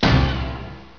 Win97 Exclamation.wav